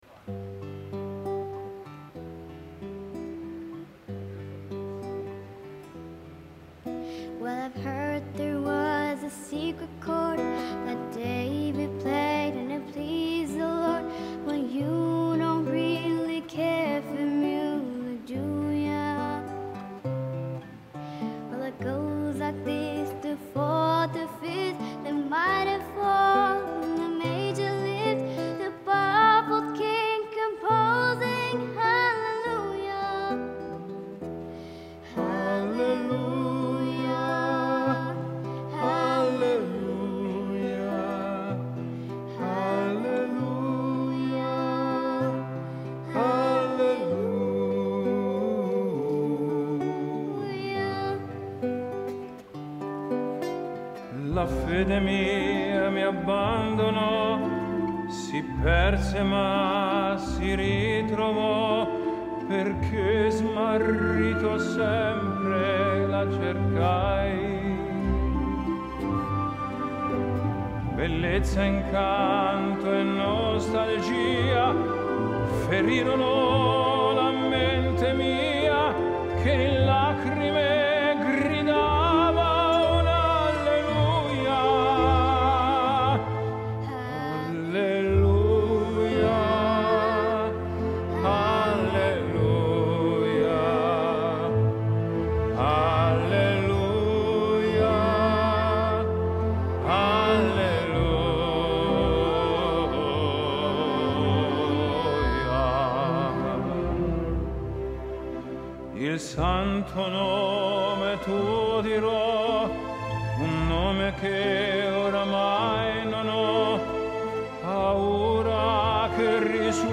Live at Teatro Regio di Parma